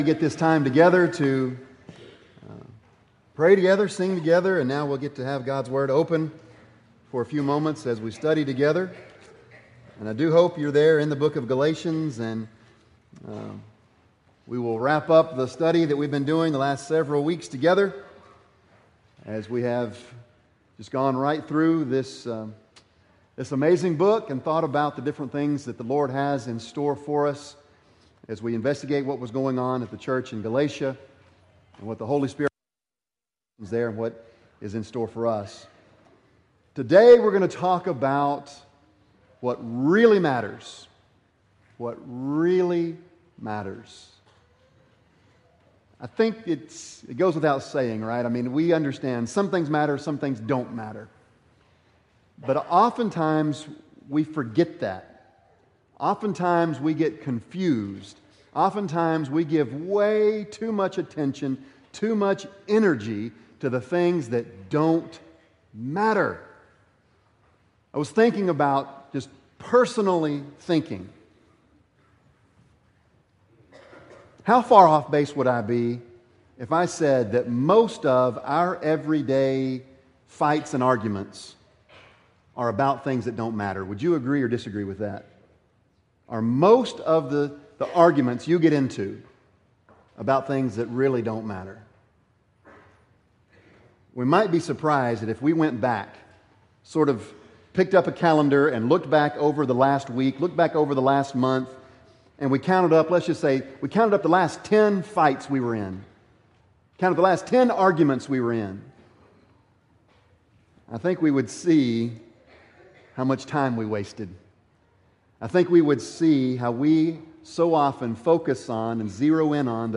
Bible Text: Galatians 6:15 | Preacher